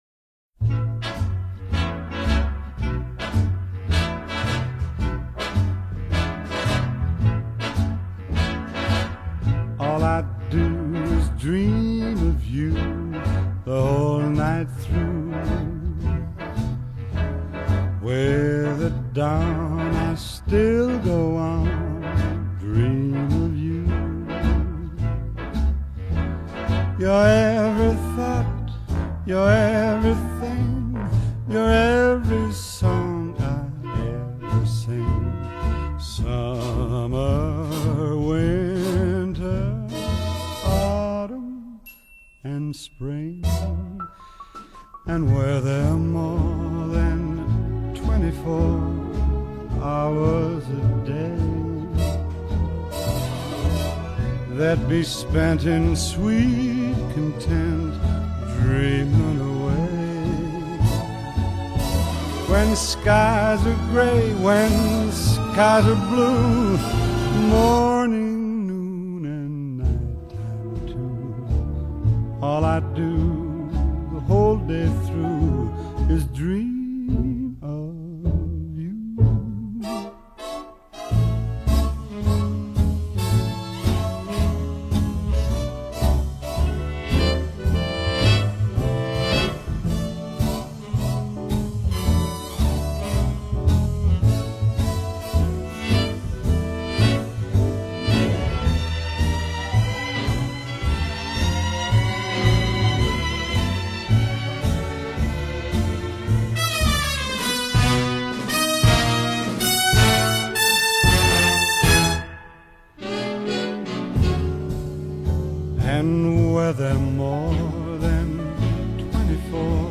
Vocal Jazz, Traditional Pop